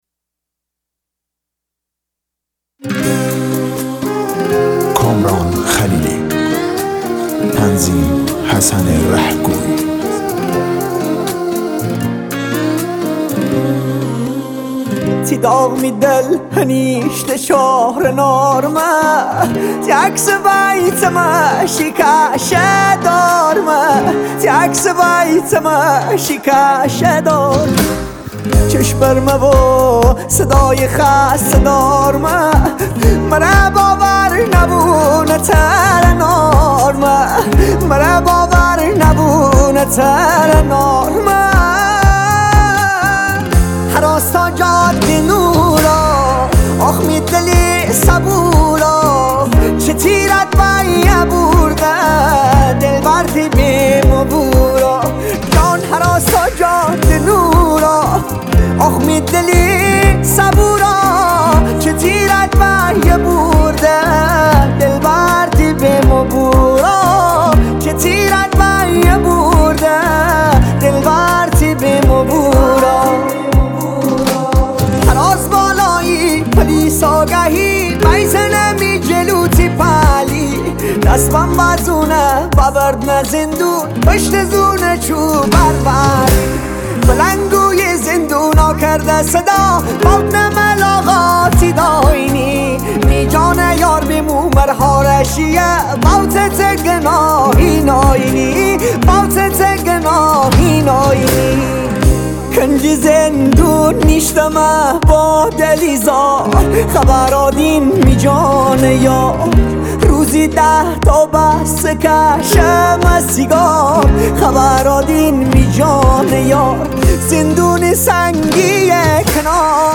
ریمیکس گیتاری